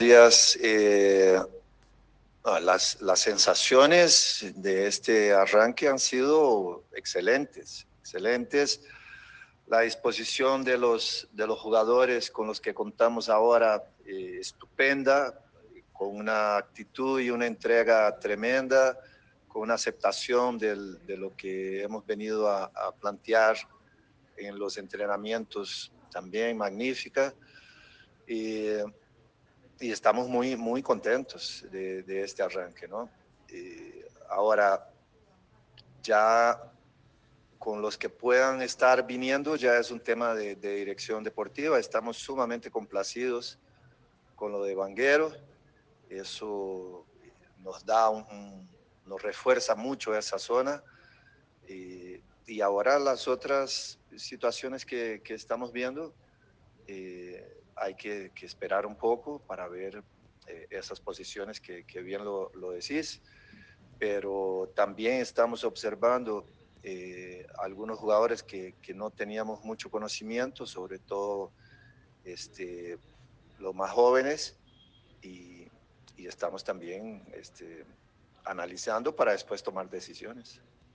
Alexandre Guimaraes – DT Nacional
“Las sensaciones de este arranque han sido excelentes. La disposición de los jugadores con lo que contamos ahora es estupenda, así como la buena actitud y entrega con la aceptación de lo que hemos venido a plantear en los entrenamientos”, sostuvo Guimaraes en rueda de prensa.